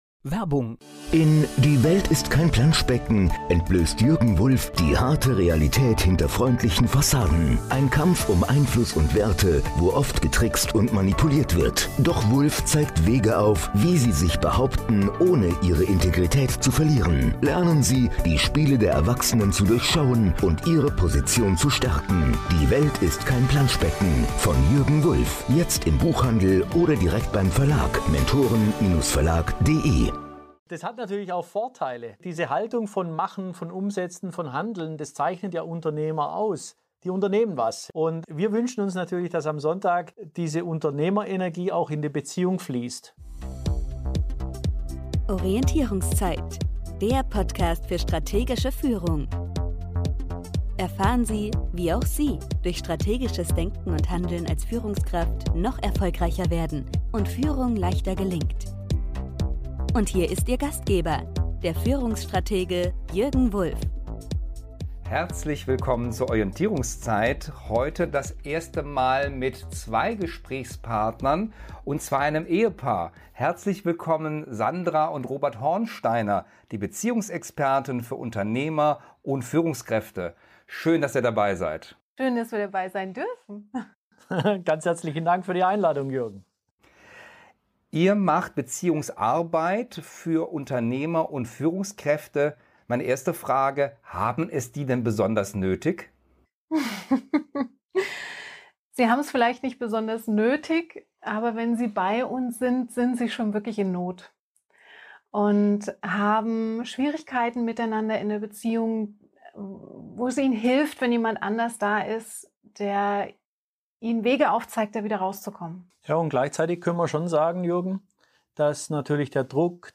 In diesem Interview geben die Beziehungsexperten Einblicke in ihre Arbeit und verraten ihre besten Tipps.